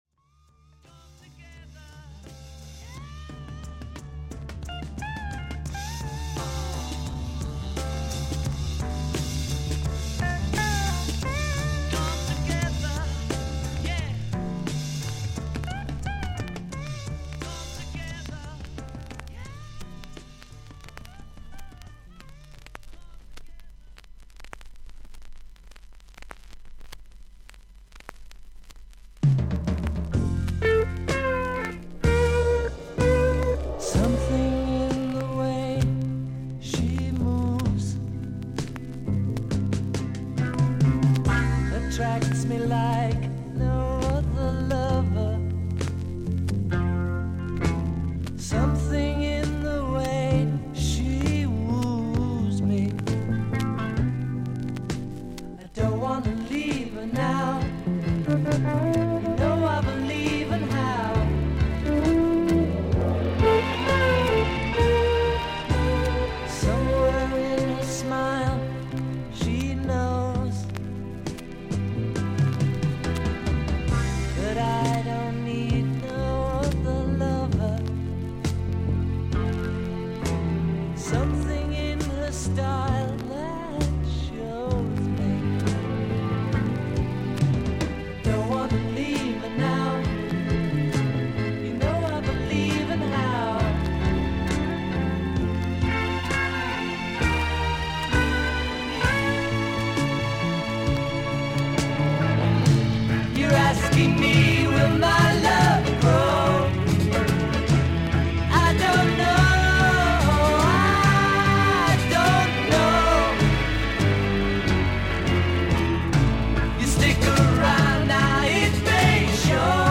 A1終盤からA3序盤まで4.5cmのキズがあり少々軽いパチノイズがあります。
ほかはVG+:長短の浅いキズ、擦りキズがあり少々軽いパチノイズの箇所あり。少々サーフィス・ノイズあり。音はクリアです。